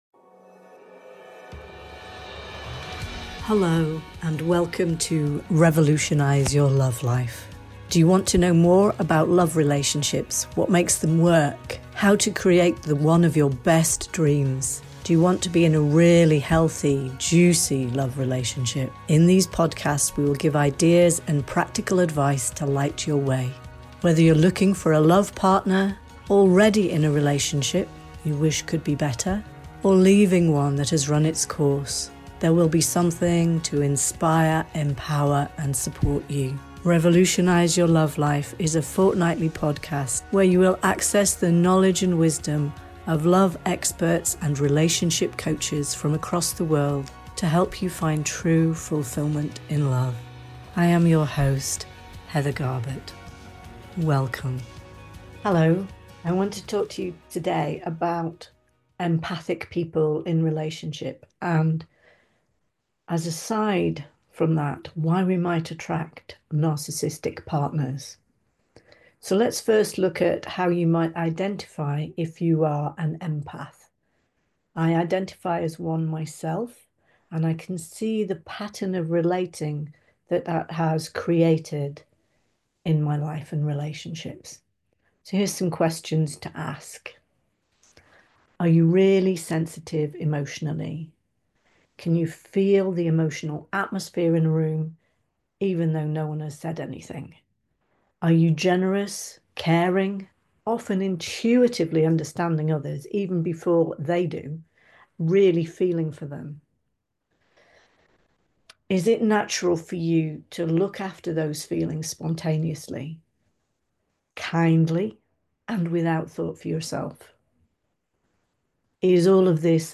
solo episode